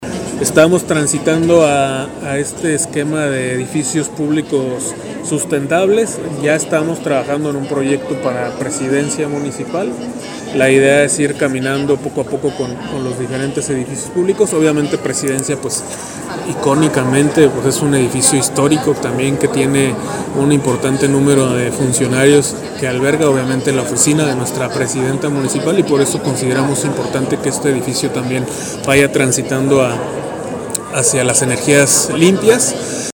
AudioBoletines
Gonzalo Guerrero Guerrero, director de obras públicas